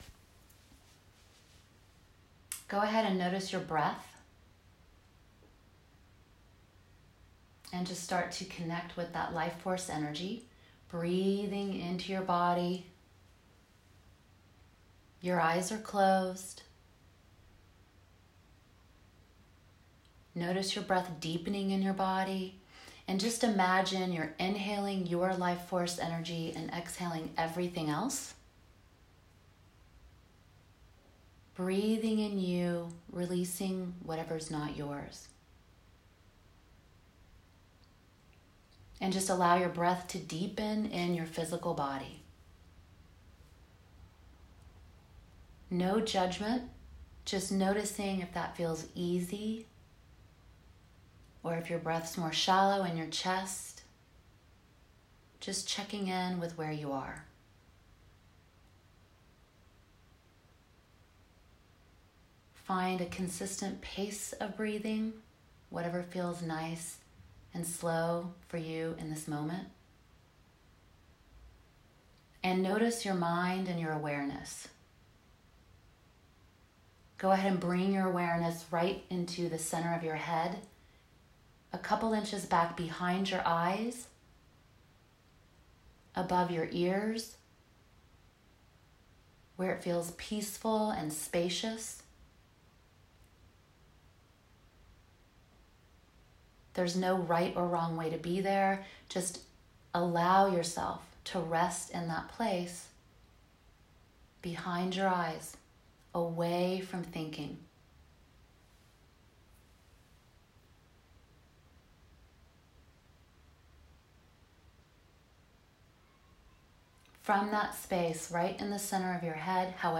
16 MINUTE GENTLE CHAKRA MEDITATION